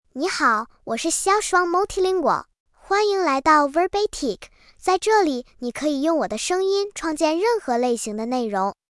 Xiaoshuang MultilingualFemale Chinese AI voice
Xiaoshuang Multilingual is a female AI voice for Chinese (Mandarin, Simplified).
Voice sample
Listen to Xiaoshuang Multilingual's female Chinese voice.